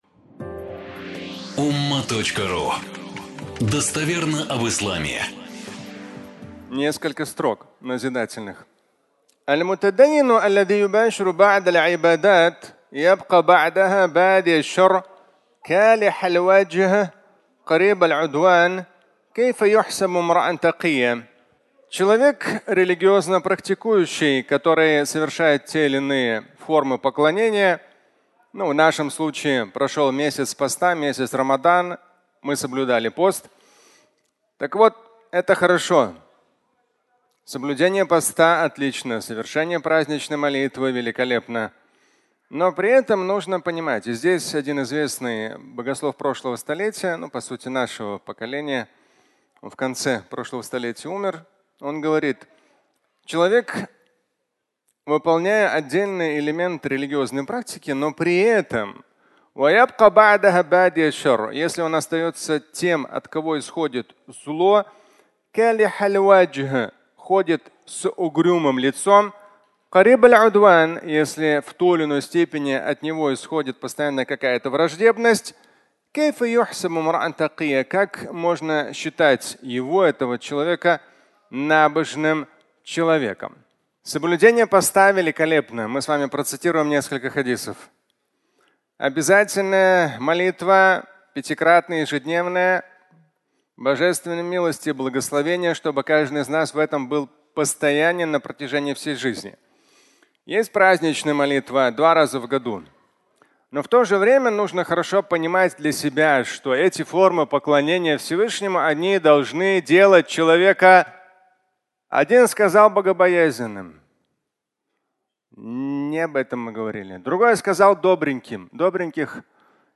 Фрагмент праздничной проповеди, в котором Шамиль Аляутдинов говорит о нравственности, цитируя хадисы на эту тему.